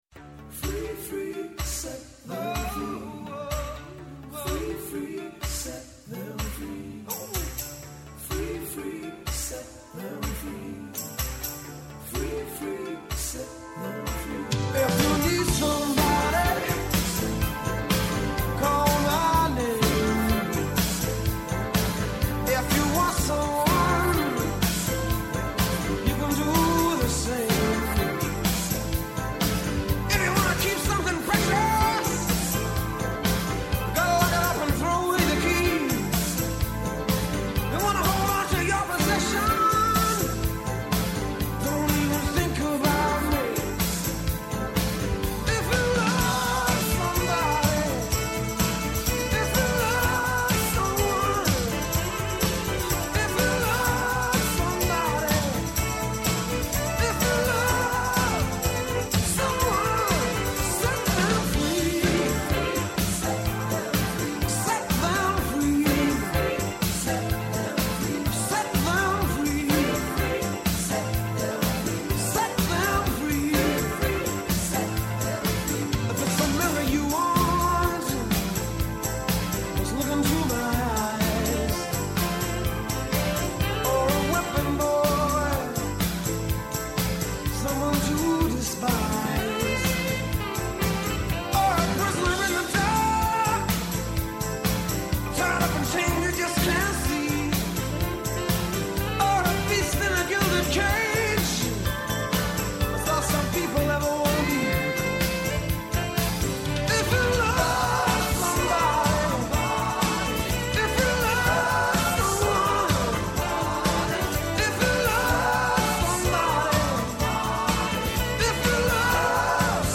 -Ο Πάνος Τσακλόγλου, Υφυπουργός Εργασίας
-Και ο Νίκος Παππάς, βουλευτής ΣΥΡΙΖΑ.
Συνεντεύξεις